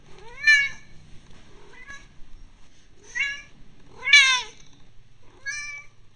描述：My cat Shari meowing. Recorded with H4n zoom Handy Recorder.
标签： miaou cats Katze miau cat meow
声道立体声